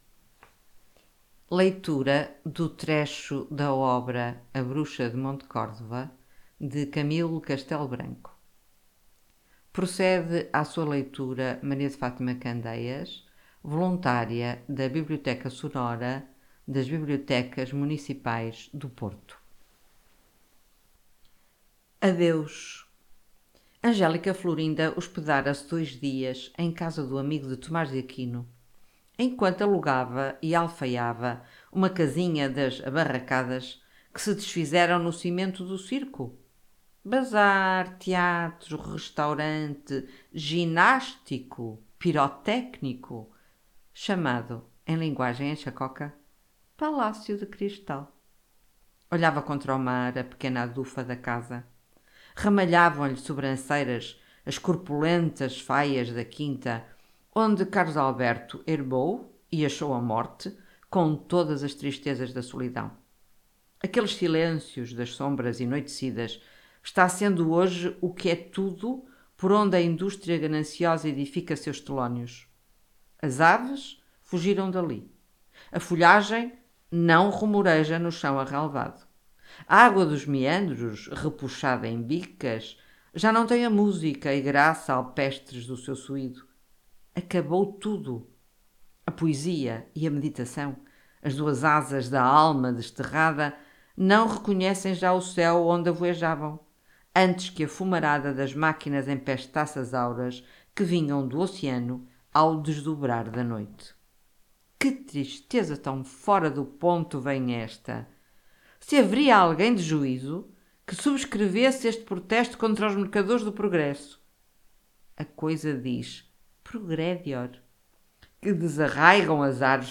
Através destes códigos, ficam disponíveis excertos da sua obra, cuidadosamente selecionados e gravados por leitores voluntários da Biblioteca Sonora, num projeto inclusivo e acessível para todos.